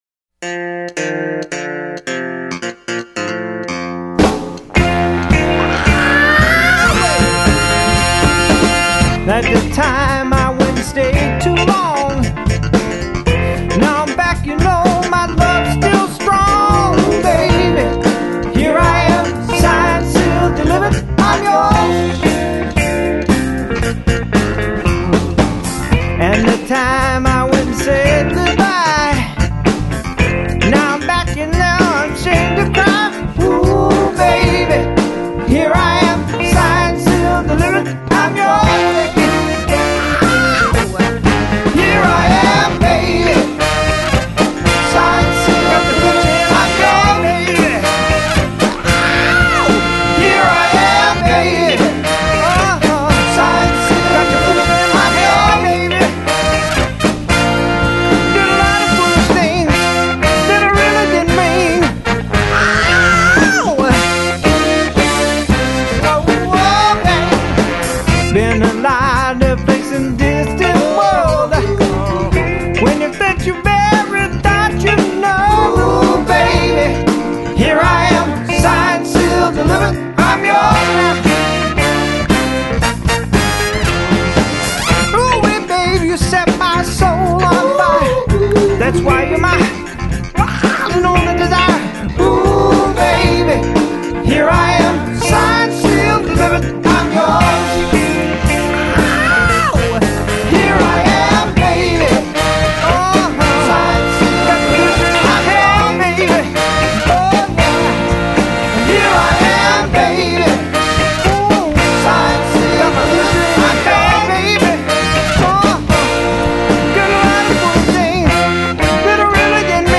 Some people call it funk. Others call it soul.
Well, this is live music not something spun on a disc.
Vocals, guitar, keyboards, percussion
Guitar, vocals
Bass guitar, vocals
Drums, vocals
Tenor Saxophone
Lead trumpet, vocals
Keyboards, vocals
Vocals, trombone